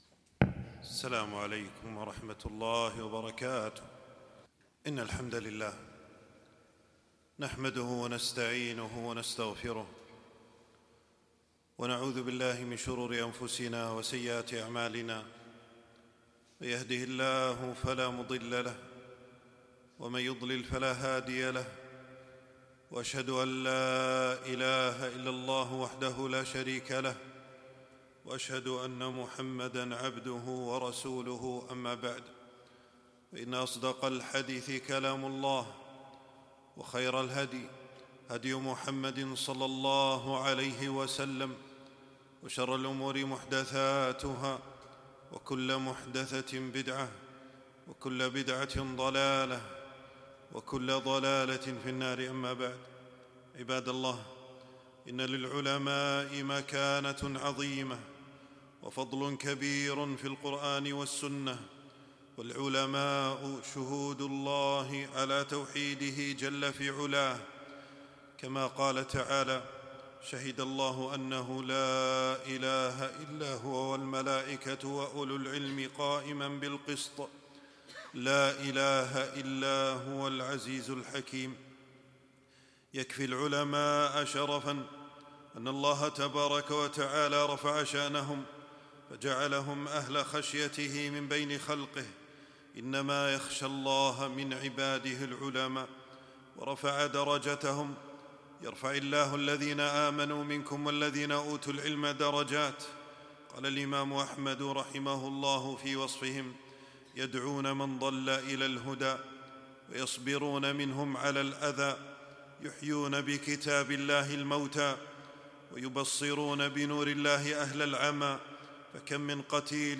تنزيل تنزيل التفريغ خطبة بعنوان: مصاب الأمة بفقد علماء الأمة .